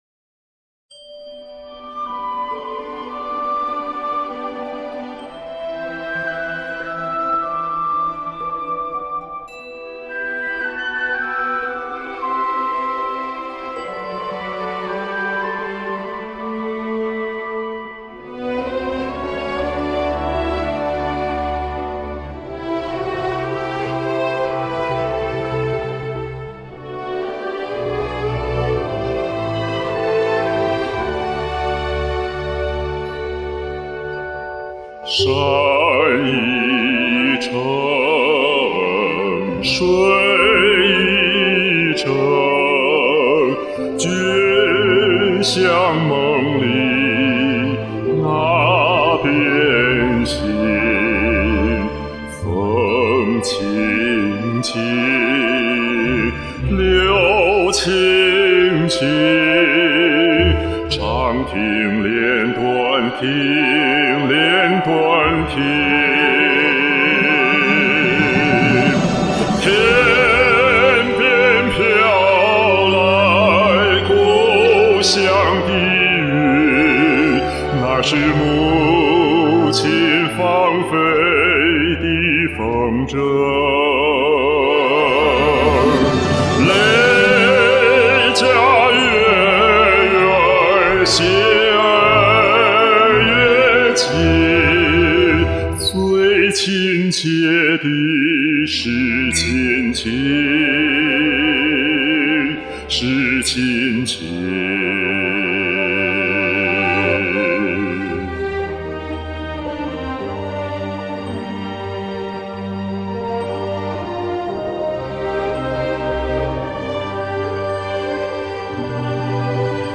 这个低音太经典了 给歌唱家献花 🌹🌹🌹
深情厚重，感人演绎！
温暖浑厚， 张力十足！春晚的感觉！